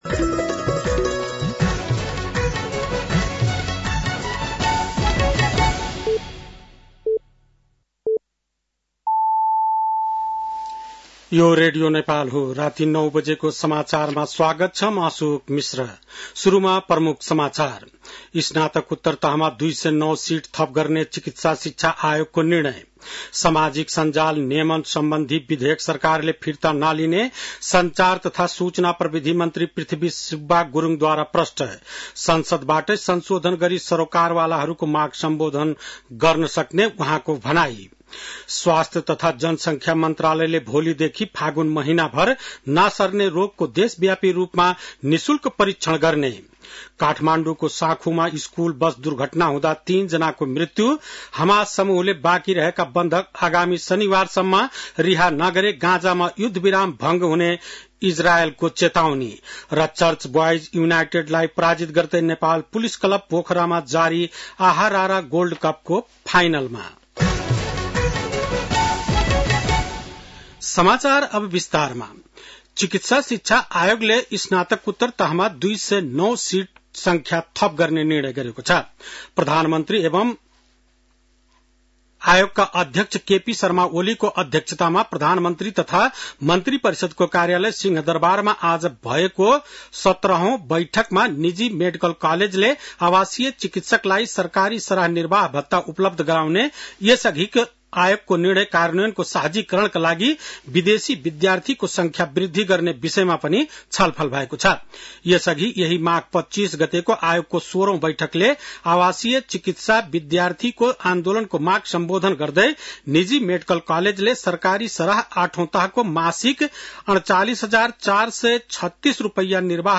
बेलुकी ९ बजेको नेपाली समाचार : १ फागुन , २०८१